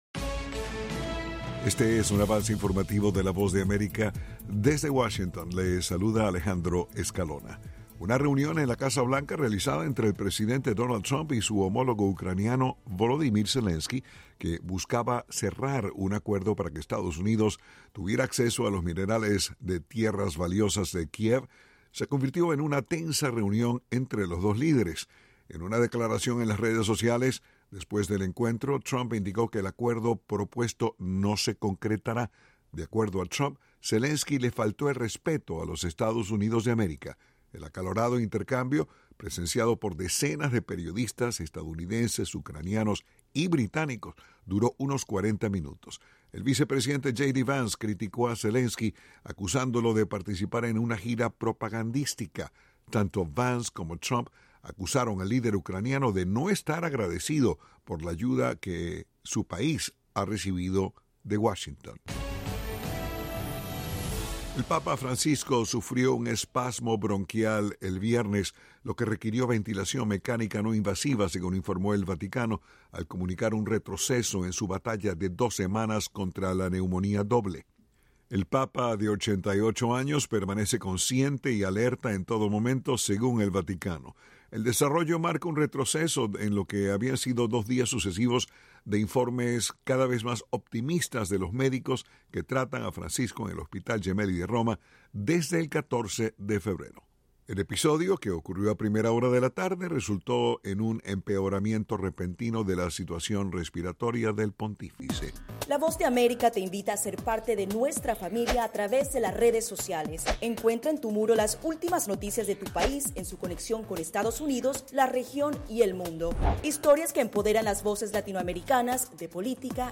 El siguiente es un avance informativo de la Voz de América.
["Avance Informativo" es un segmento de noticias de la Voz de América para nuestras afiliadas en la región de América Latina y el Caribe].